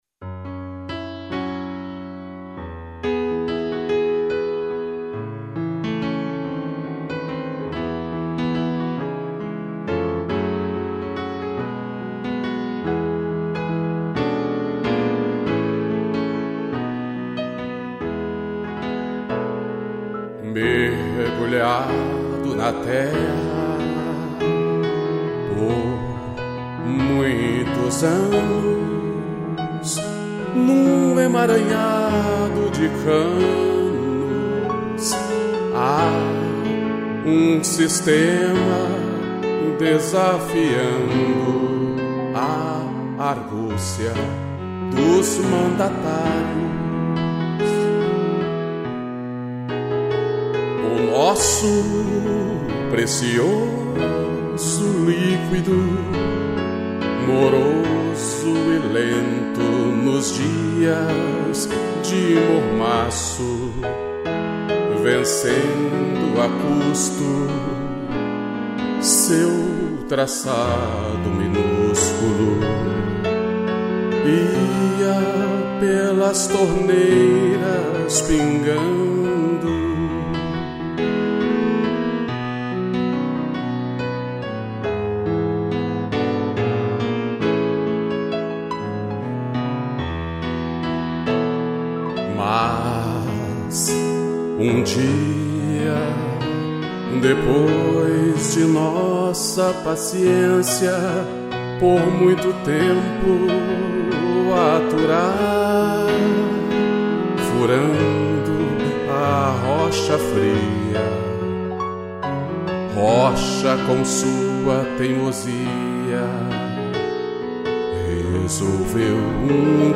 piano e cello